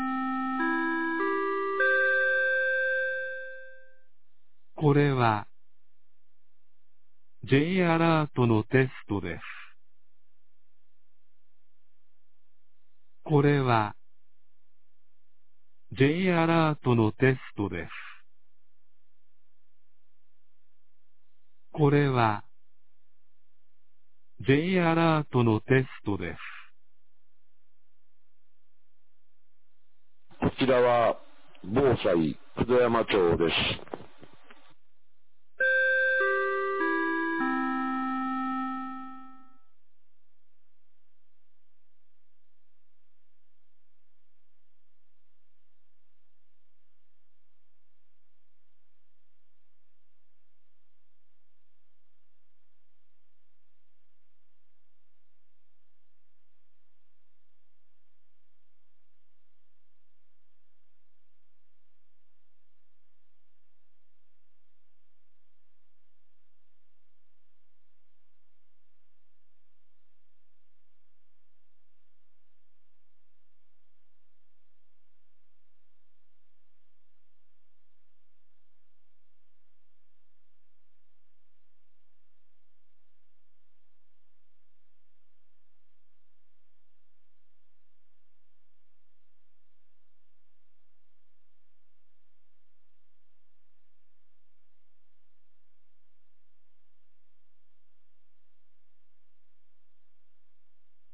2024年05月22日 11時02分に、九度山町より全地区へ放送がありました。